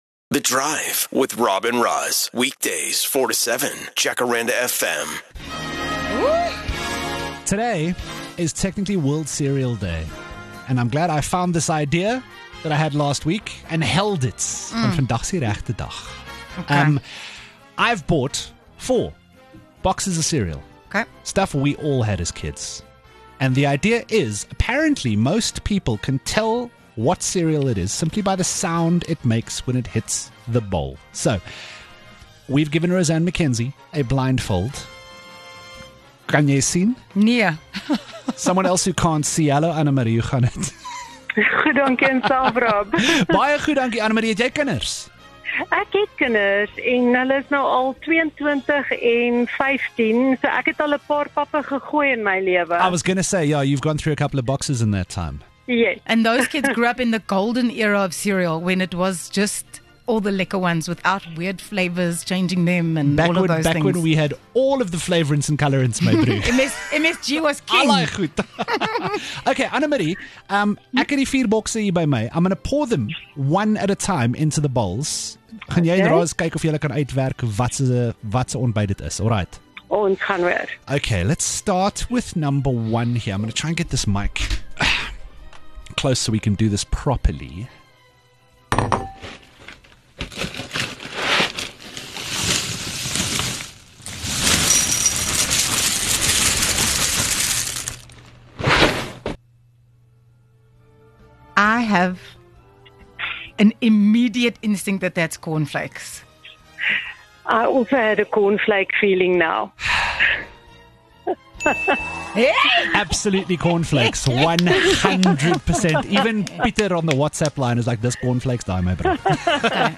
7 Mar Can you identify the cereal just by the sound it makes?